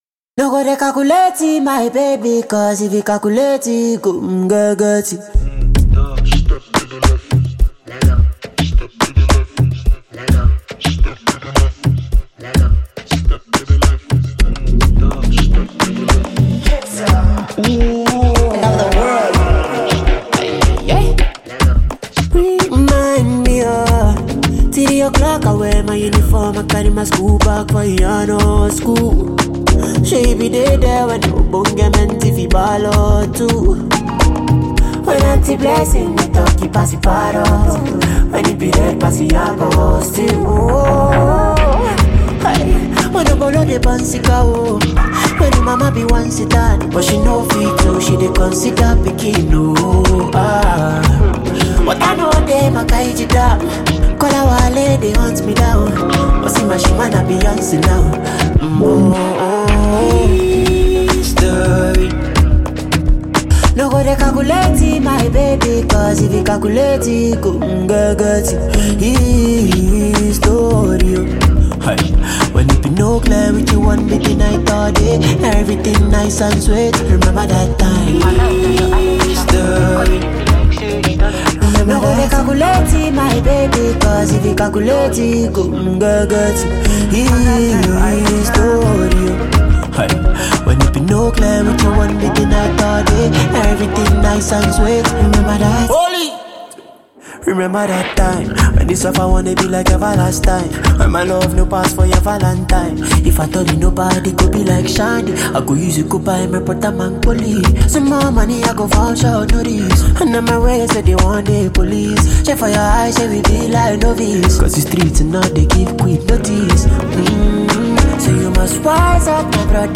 Category: Nigerian / African Music Genre: Afrobeats